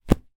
zapsplat_household_pocket_photograph_album_close_11104.mp3